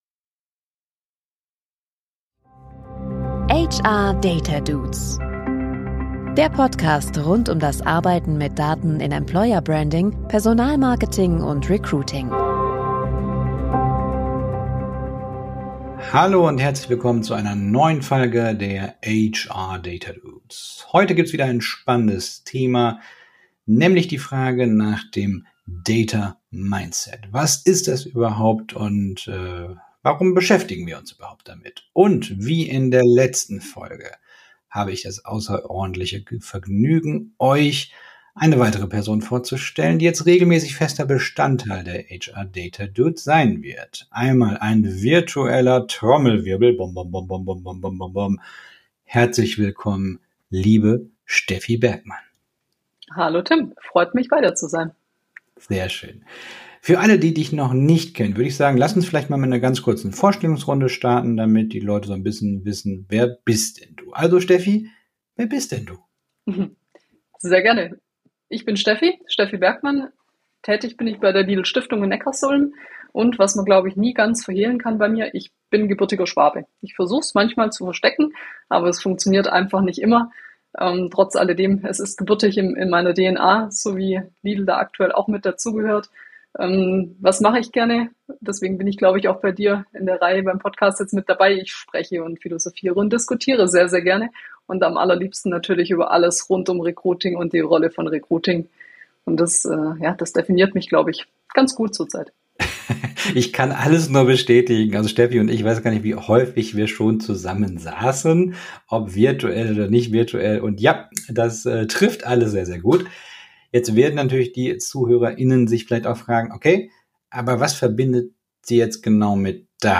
Freut euch auf ein lebhaftes Gespräch über Kennzahlen, Datenliebe und die Bedeutung, immer wieder das „Warum“ zu hinterfragen.